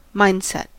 Ääntäminen
Synonyymit mentality place Ääntäminen US : IPA : [ˈmaɪnd.ˌsɛt] Haettu sana löytyi näillä lähdekielillä: englanti Määritelmät Substantiivit A way of thinking ; an attitude or opinion , especially a habitual one.